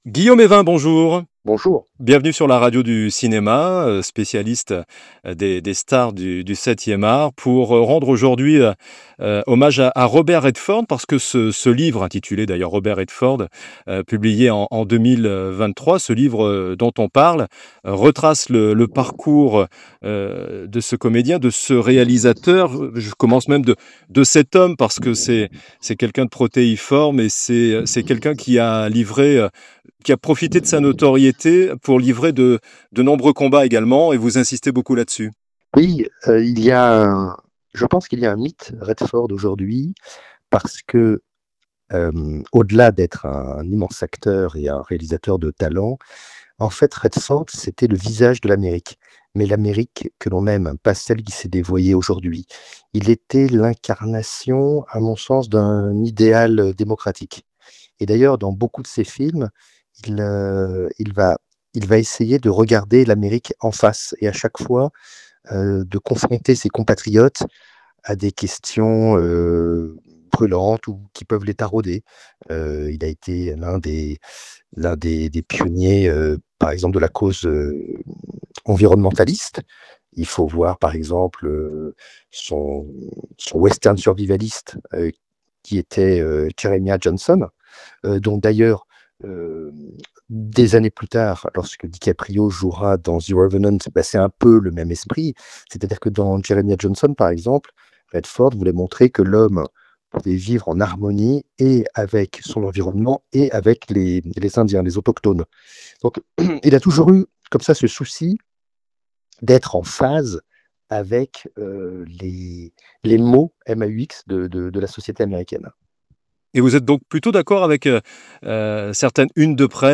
2. Podcasts cinéma : interviews | La Radio du Cinéma